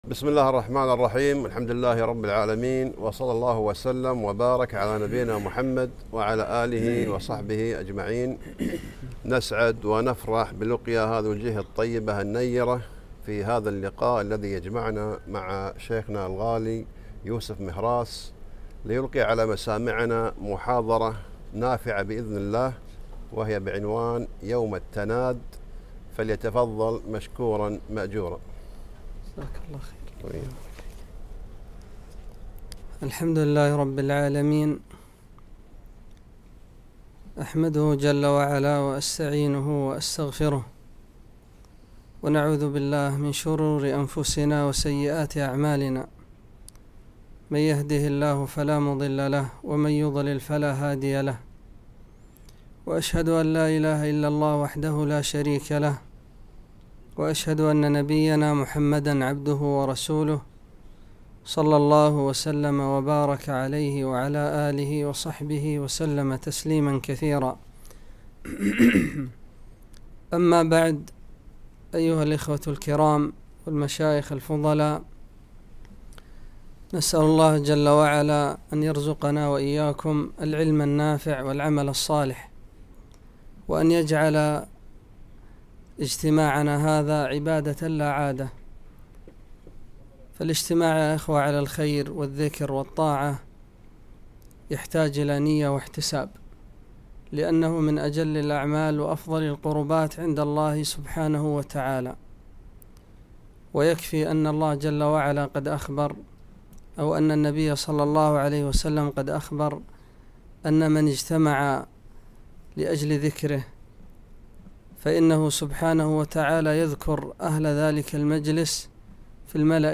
محاضرة - يوم التناد